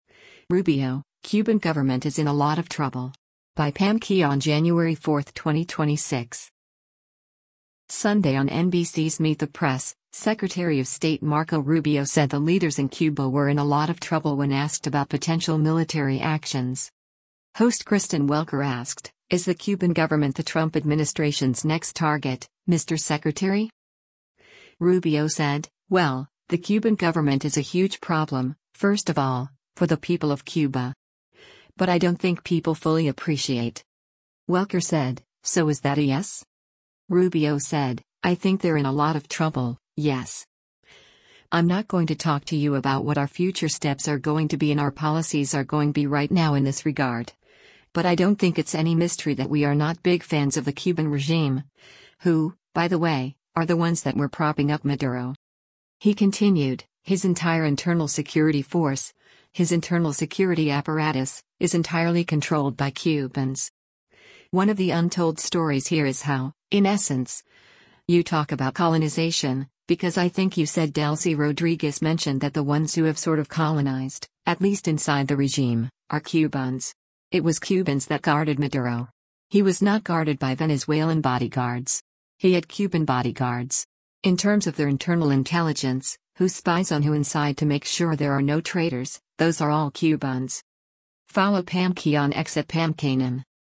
Sunday on NBC’s “Meet the Press,” Secretary of State Marco Rubio said the leaders in Cuba were in a lot of trouble when asked about potential military actions.